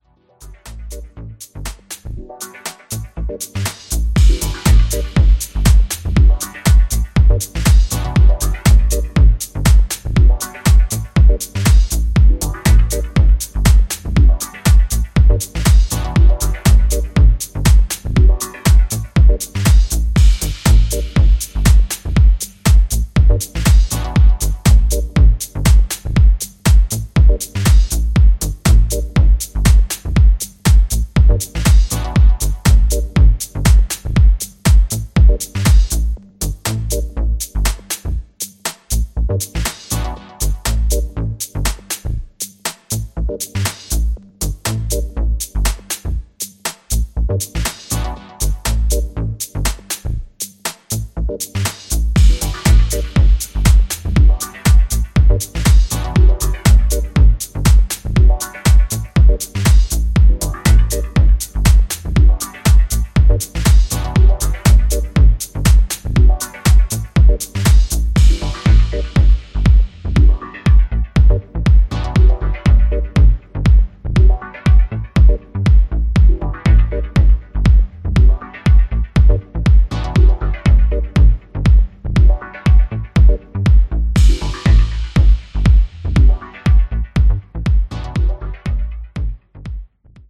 US産らしい厚みあるミニマル/ハウスを融合したナイスなデトロイト・ディープ・ハウスを展開する全4トラック。
ジャンル(スタイル) HOUSE / DEEP HOUSE